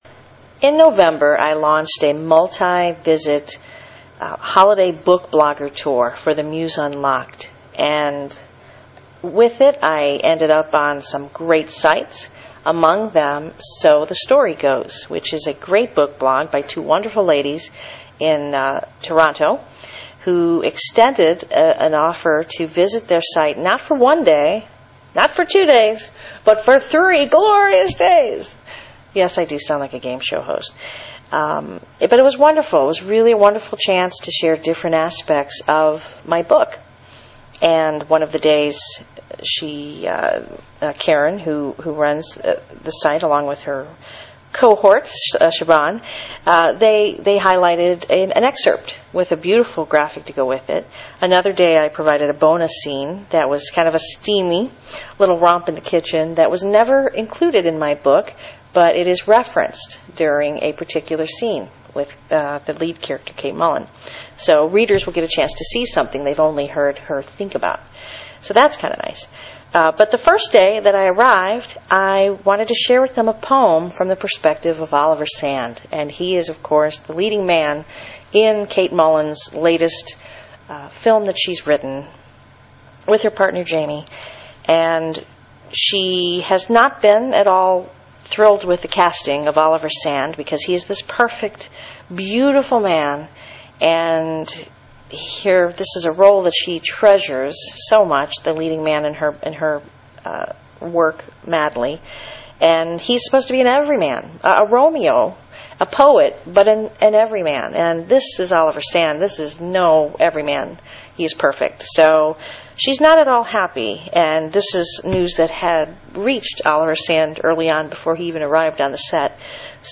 provides a reading of the piece which reflects the voice of the book's leading man, Oliver Sand.